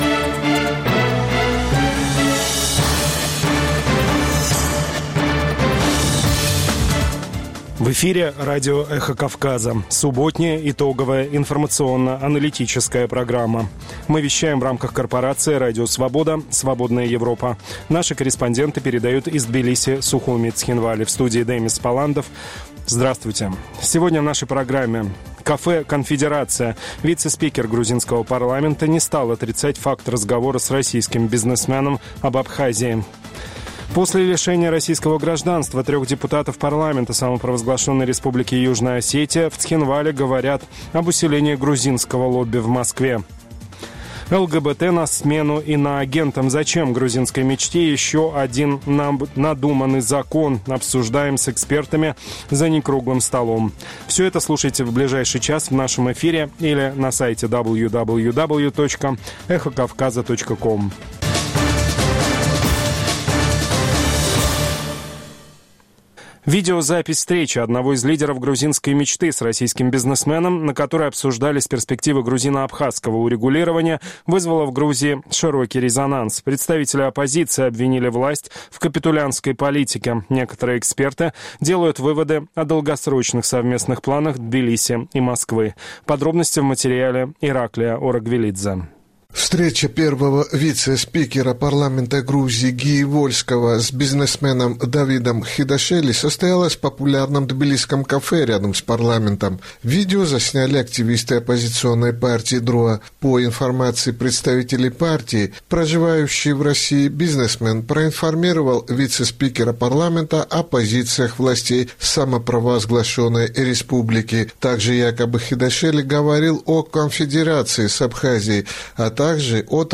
Новости, репортажи с мест, интервью с политиками и экспертами, круглые столы, социальные темы, международная жизнь, обзоры прессы, история и культура.